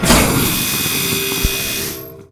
pump.ogg